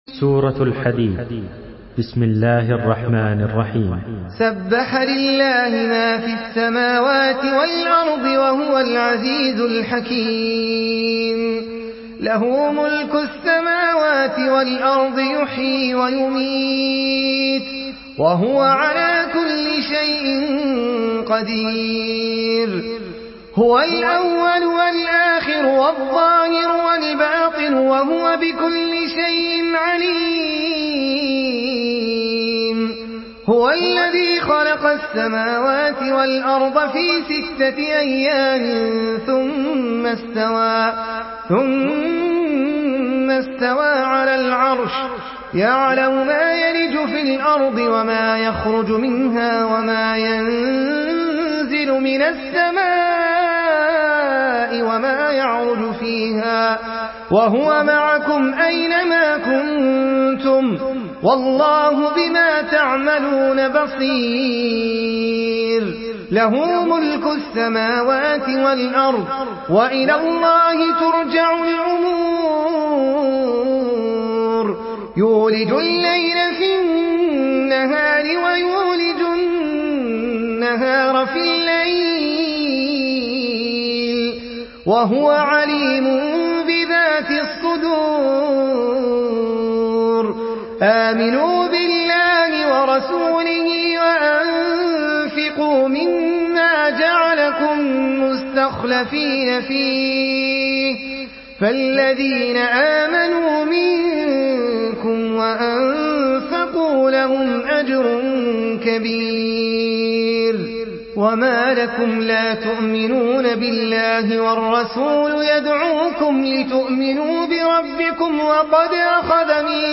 سورة الحديد MP3 بصوت أحمد العجمي برواية حفص
مرتل